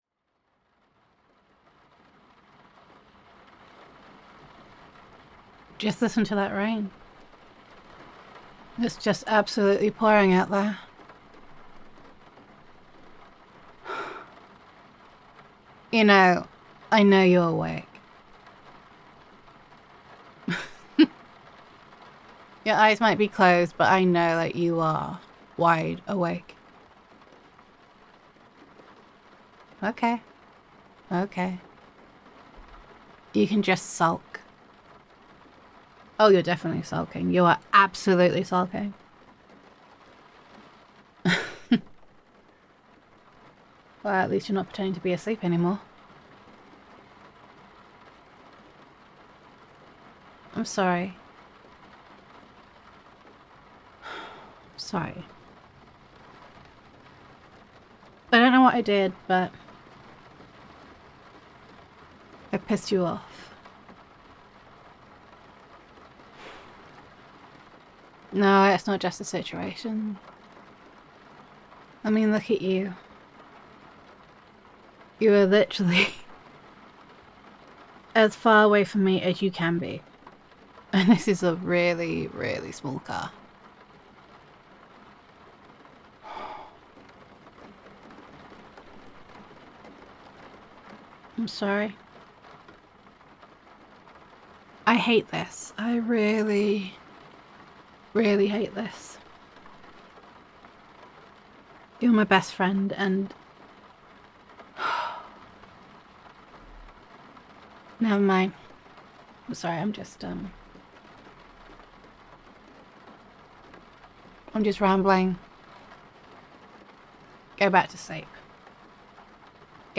[F4A] Rained Out [Love Confession][Friends to Lovers][Camping][Best Friend Roleplay][Heartfelt][Sleeping In the Car][Heavy Rain][First Kiss][I Miss You][Gender Neutral][Rain Ruins a Camping Trip but Might Save a Friendship]